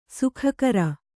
♪ sukhakara